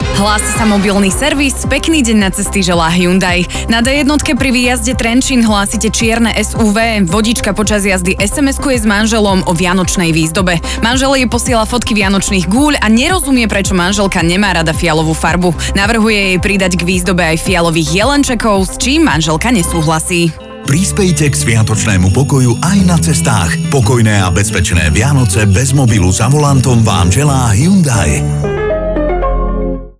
V rádio spotoch sme vytvorili nový formát - mobilný servis.